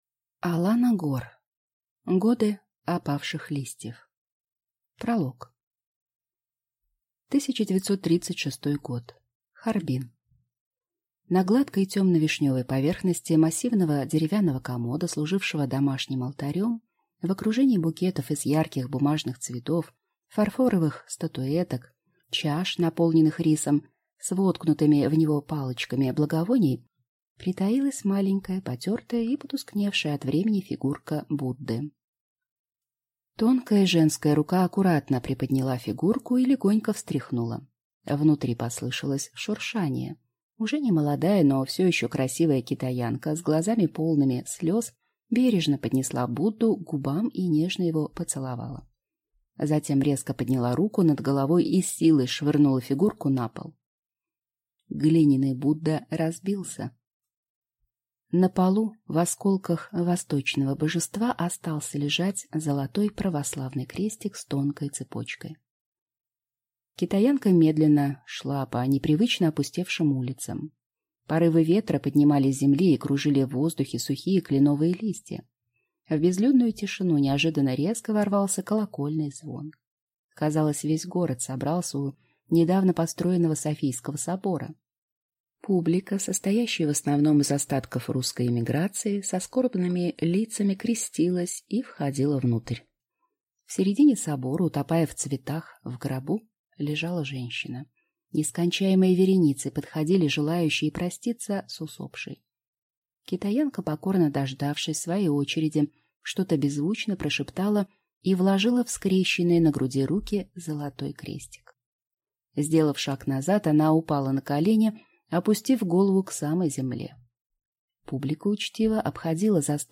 Аудиокнига Годы опавших листьев | Библиотека аудиокниг